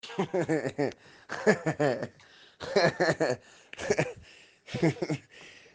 Play Risada Falsa - SoundBoardGuy
Play, download and share risada falsa original sound button!!!!